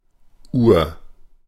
Ääntäminen
Synonyymit genuin Ääntäminen Tuntematon aksentti: IPA: /uːɐ̯/ IPA: [ʔuːɐ̯] Haettu sana löytyi näillä lähdekielillä: saksa Käännös Ääninäyte Adjektiivit 1. original US Luokat Etuliitteet Vanhasta yläsaksasta johdetut sanat